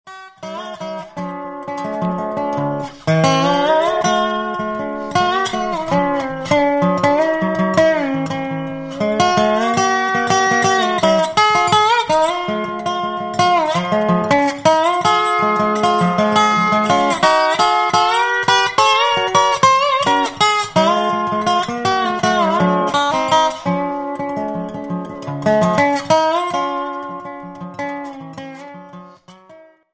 Dobro Beispiel 2
Dobro2.mp3